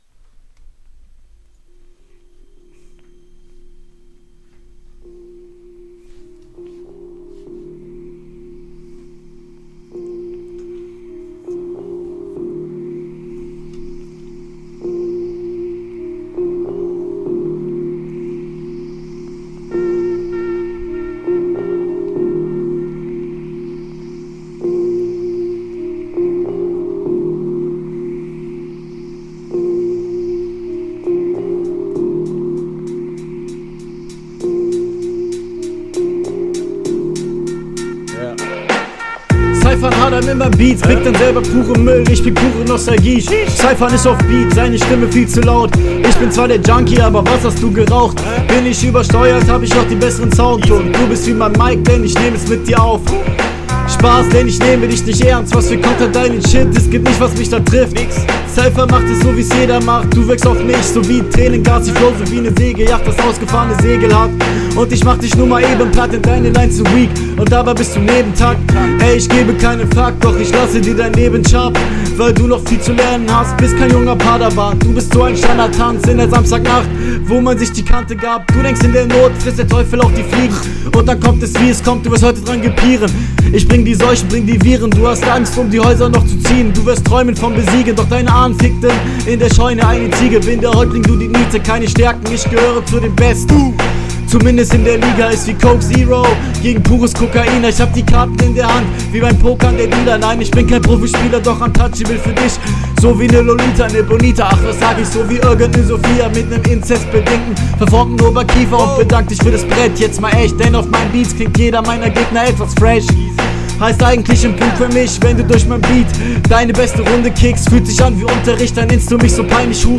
Eine Aufnahme durchlaufen zu lassen ist nie gut. Hier klingst du nochmal ein ticken fresher.
Flowlich besser als der Gegner, wirkt wirklich chillig.